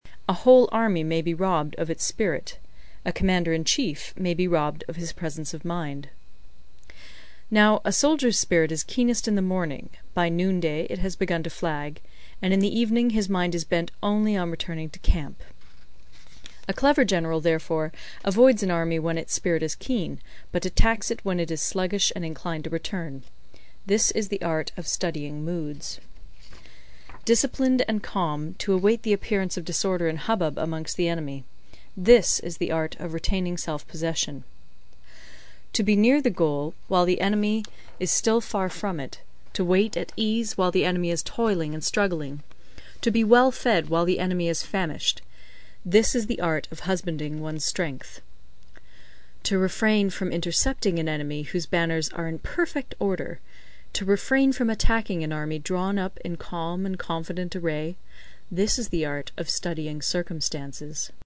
有声读物《孙子兵法》第43期:第七章 军争(5) 听力文件下载—在线英语听力室